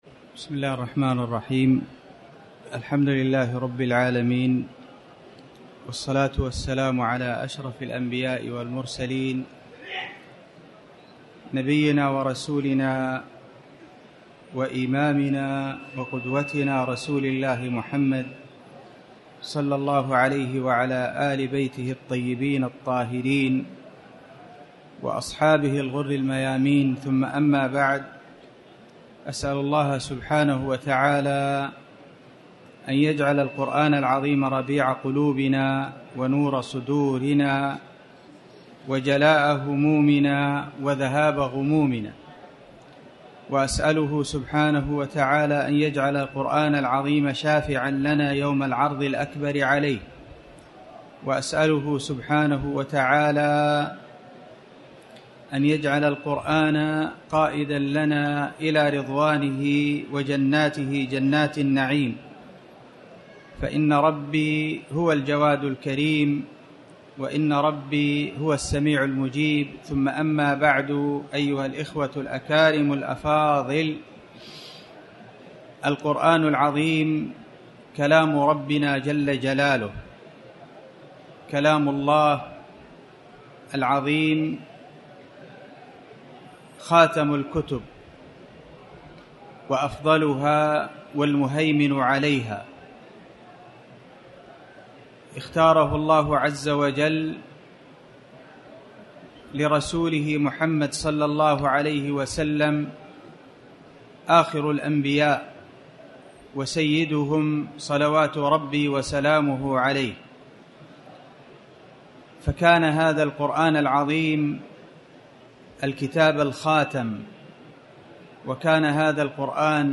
المكان: المسجد الحرام
محاضرة-قولة-تعالى-والذين-يؤتون-ماآتووقلوبهم-وجلة999.mp3